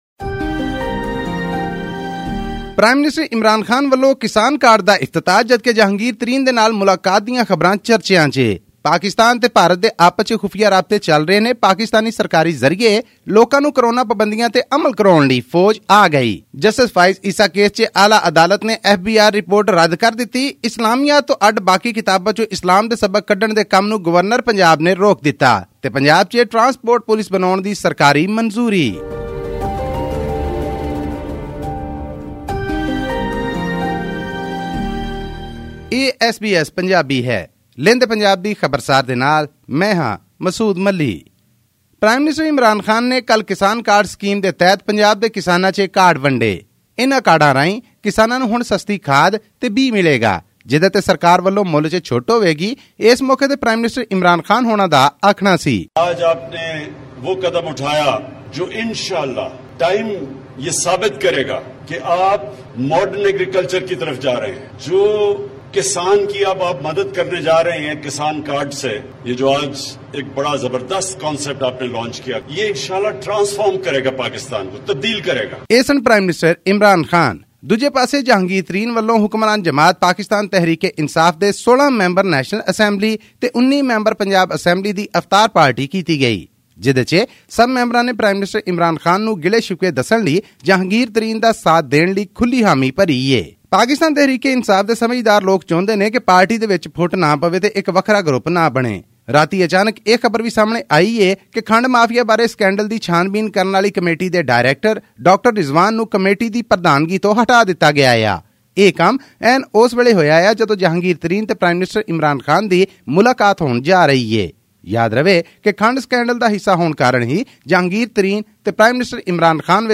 The Pakistan government has brought in troops in 16 major cities across the country to assist authorities in enforcing measures meant to contain the spread of the coronavirus, including the wearing of masks in public and the closing of non-essential businesses after 6 pm. Tune into this podcast for a weekly news update from Pakistan.